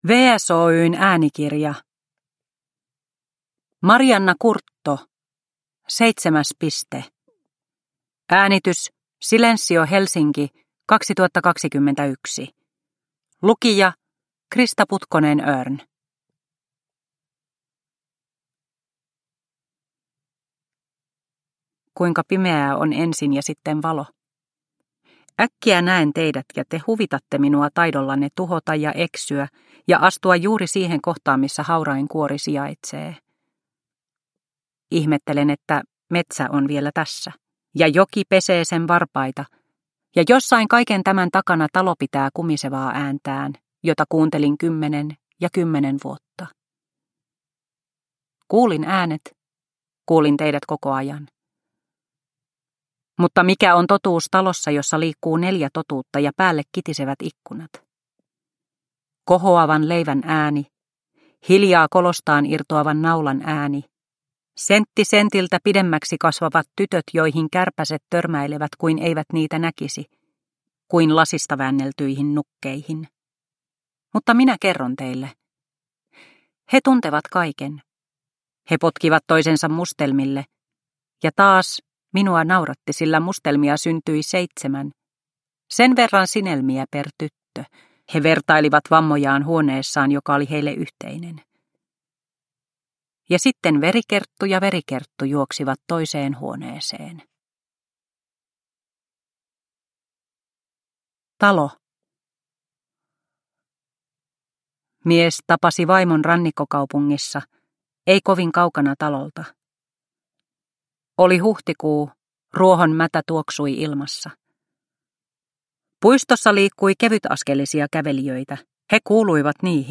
Seitsemäs piste – Ljudbok – Laddas ner